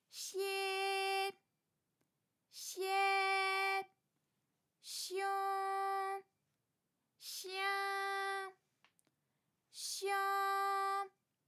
ALYS-DB-002-FRA - First publicly heard French UTAU vocal library of ALYS